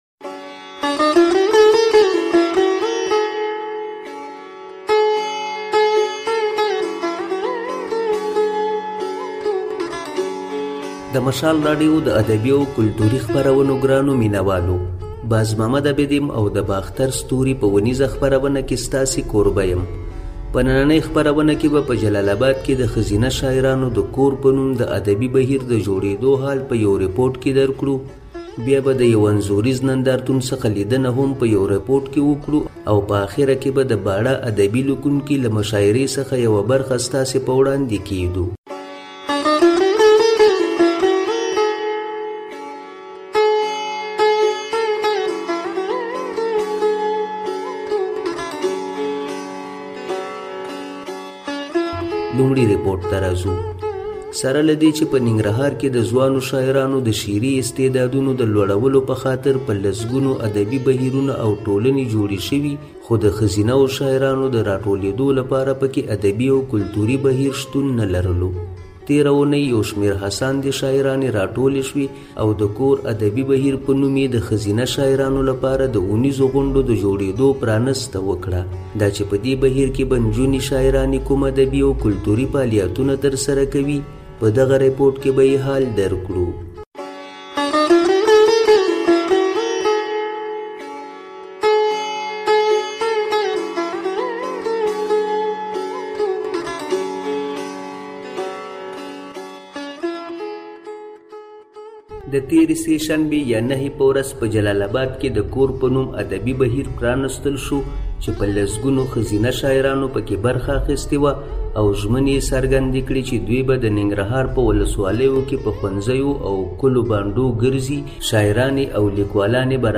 د باختر ستوري په خپرونه کې په جلال اباد کې د ښځينه شاعرانو د ادبي بهير د جوړيدو په اړه رپوټ، د انځوريز نندارتون په اړه هم يو رپوټ او د باړه ادبي ليکونکو جرګې نه د مشاعرې يوه برخه ځای شوې ده.